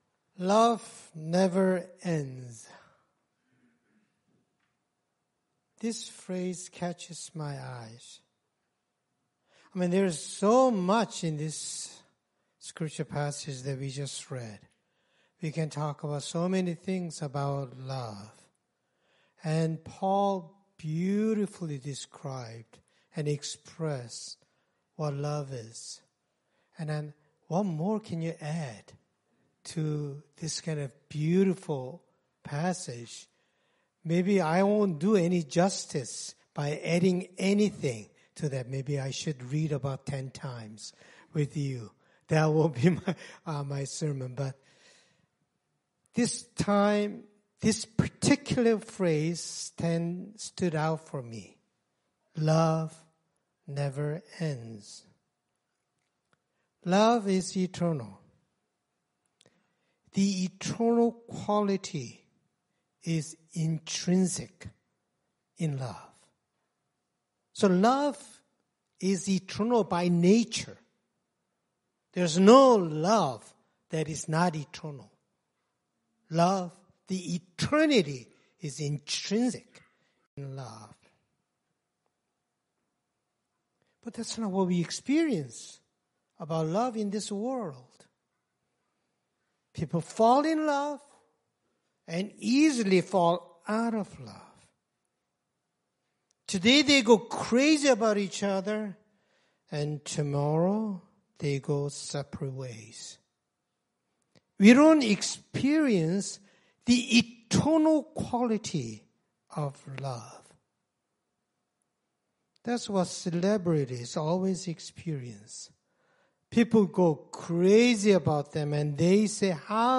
Scripture Passage 1 Corinthians 13:1-13 Worship Video Worship Audio Sermon Script Love never ends.